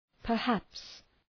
Προφορά
{pər’hæps}